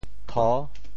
唾 部首拼音 部首 口 总笔划 11 部外笔划 8 普通话 tuò 潮州发音 潮州 to6 文 中文解释 唾〈名〉 (形声。